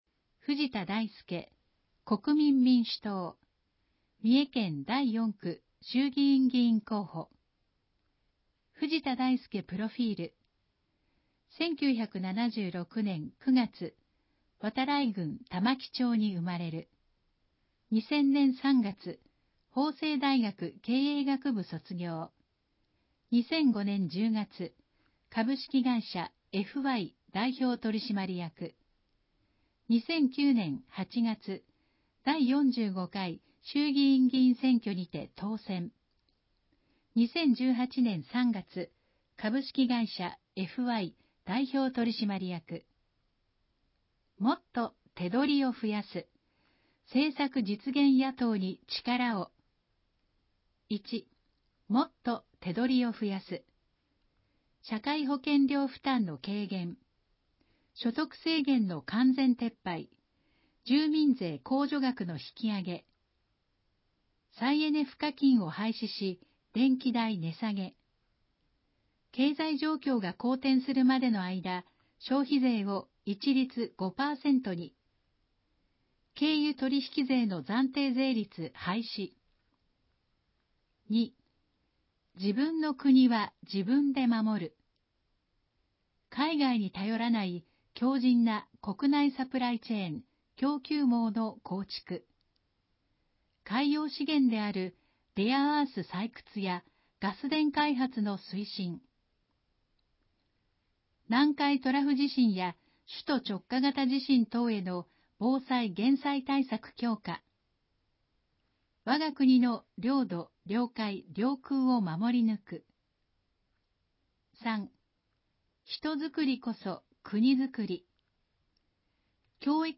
選挙公報の音声読み上げ対応データ（候補者提出）